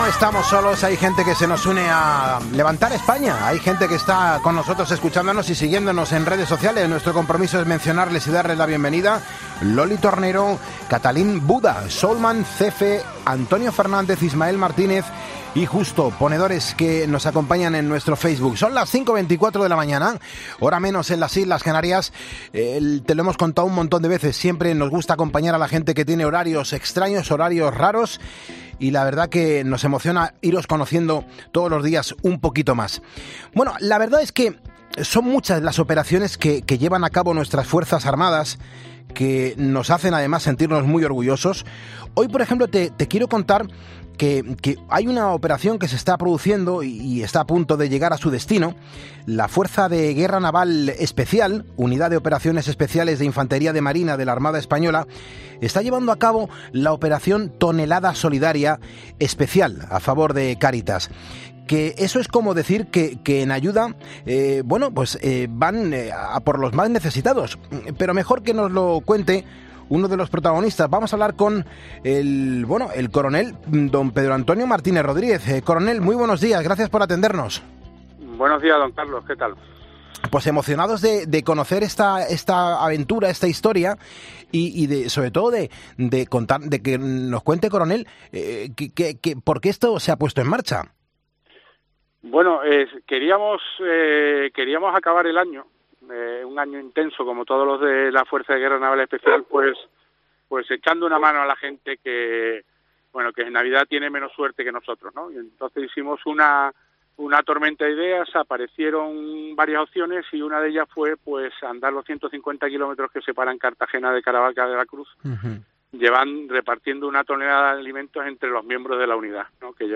ha estado en directo en 'Poniendo las Calles'.